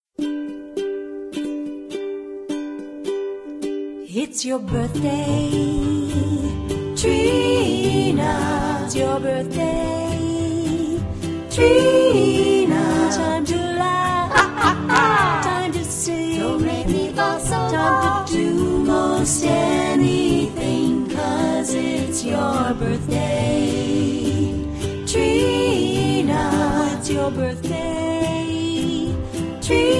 Funny & Unique Happy Birthday Ringtones